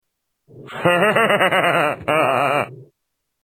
Laugh 2
Category: Television   Right: Personal